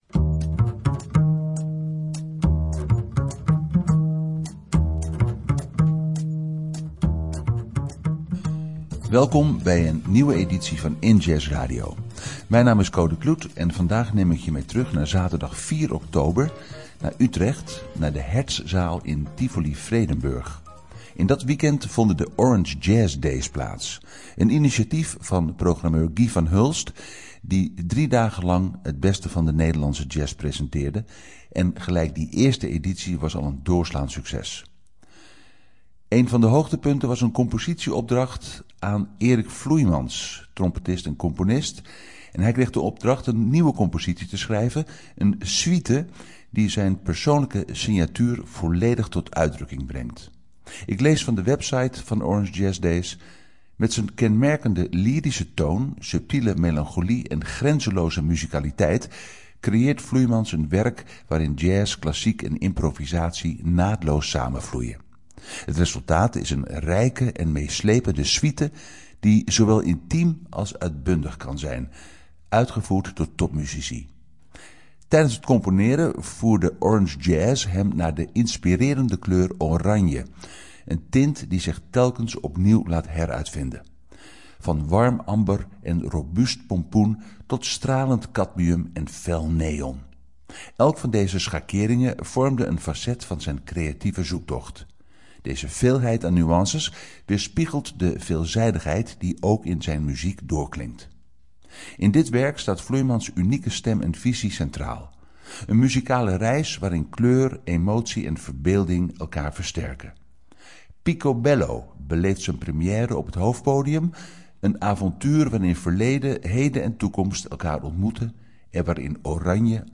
Centraal staat de promotie van jazz en beyond. Deze keer Orange Jazz Days met Eric Vloeimans' Pico Bello Extended.